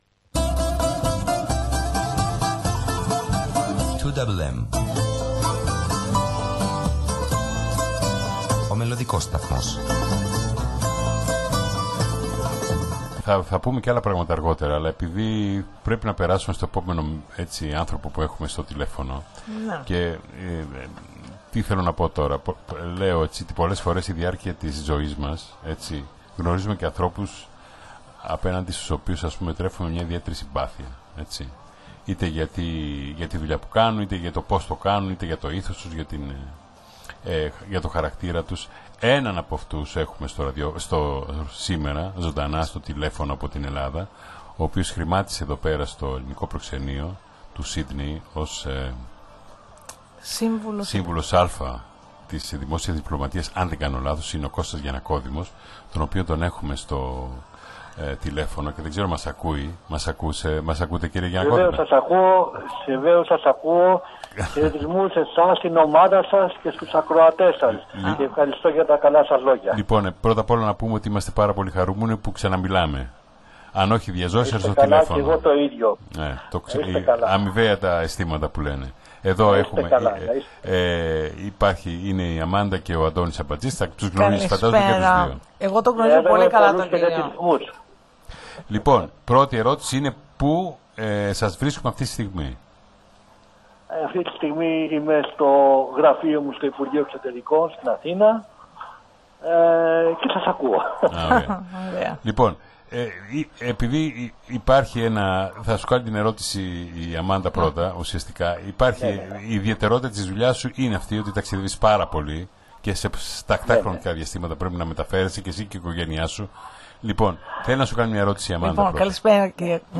τηλεφωνική ζωντανή συνέντευξη